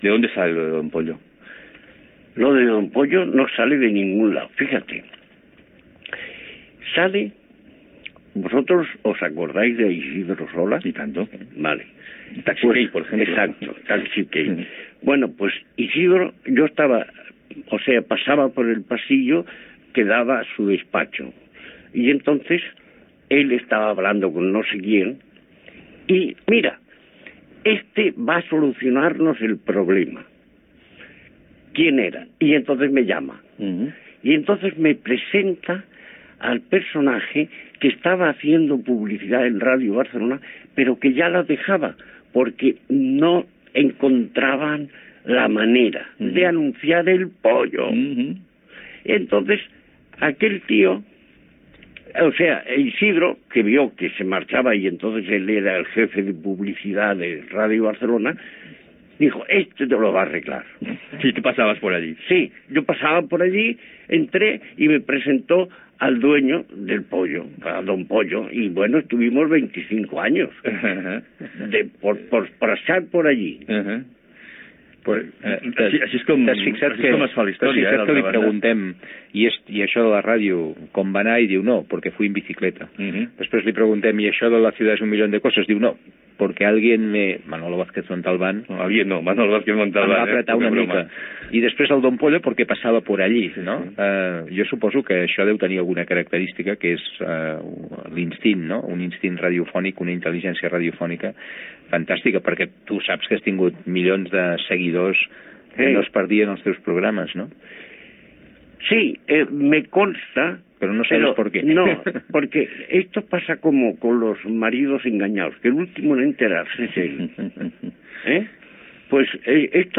92b0574d803f77fb752f60d2bc5fea4c5433a987.mp3 Títol COM Ràdio Barcelona - Aquell dia Emissora COM Ràdio Barcelona Cadena COM Ràdio Titularitat Pública nacional Nom programa Aquell dia Descripció Programa dedicat a Luis Arribas Castro i l'any 1976. Gènere radiofònic Entreteniment